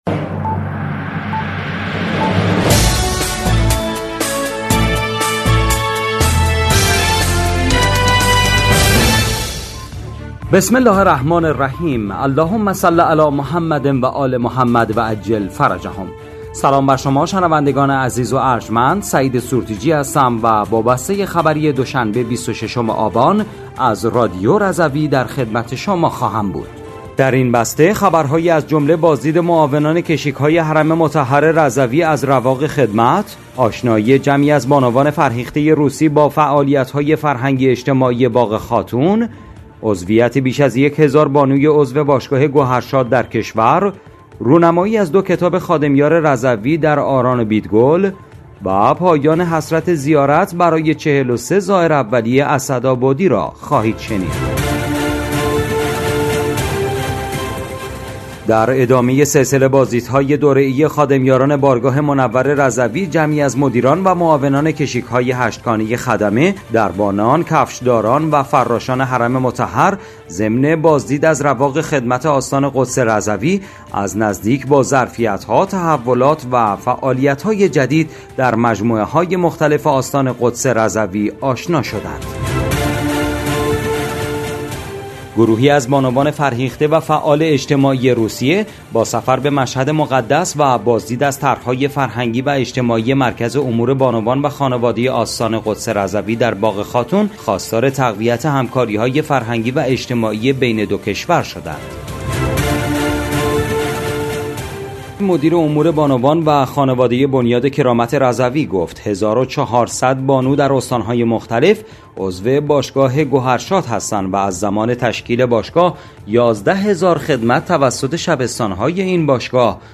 بسته خبری ۲۶ آبان ۱۴۰۴ رادیو رضوی؛